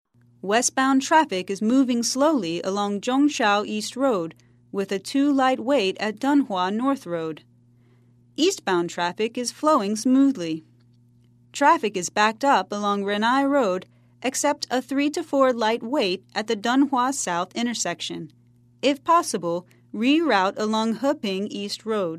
在线英语听力室赖世雄英语新闻听力通 第84期:拥堵的交通的听力文件下载,本栏目网络全球各类趣味新闻，并为大家提供原声朗读与对应双语字幕，篇幅虽然精短，词汇量却足够丰富，是各层次英语学习者学习实用听力、口语的精品资源。